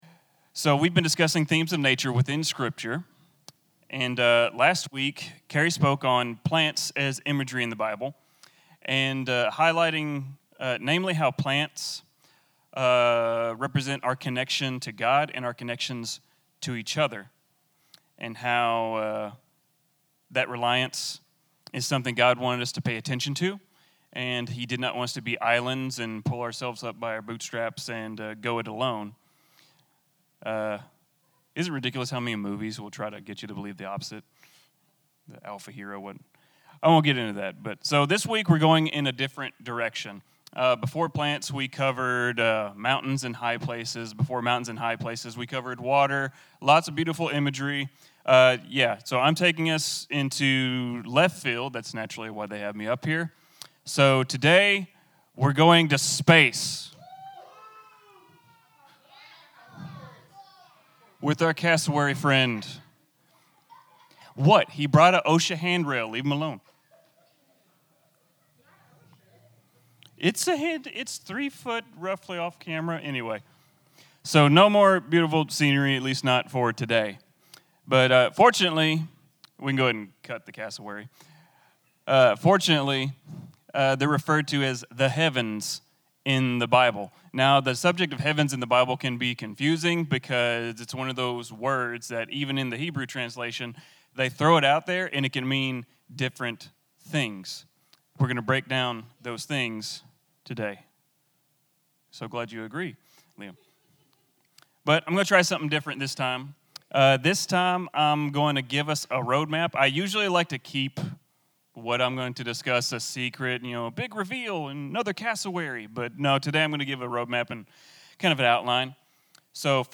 Sermons | Project Community